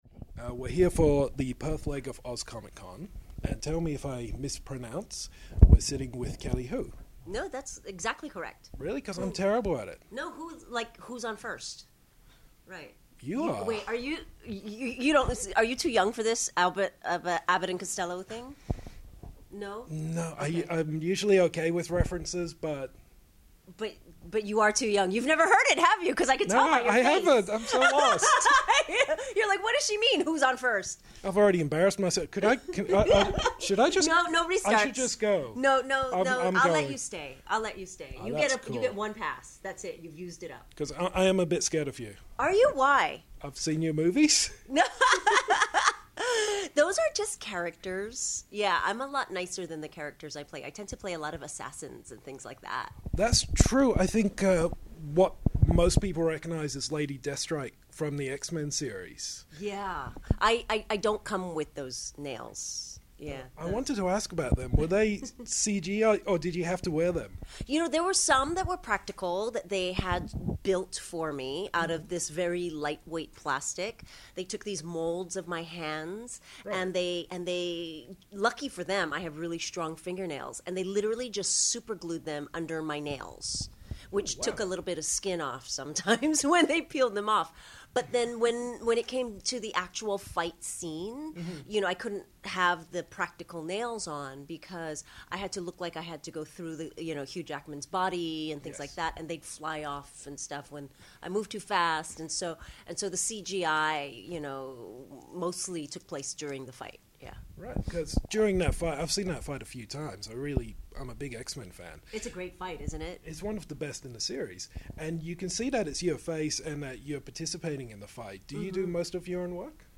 Exclusive Interview with Kelly Hu!
Before the doors open we took the chance to sit down with ass-kicking Kelly Hu. Best known for shredding Wolverine as Lady Deathstrike in X-Men 2 and as China White in Arrow, Kelly had plenty to say about acting, modelling and beating up pretty boys!
house-of-geekery-kelly-hu-oz-comic-con-2016.mp3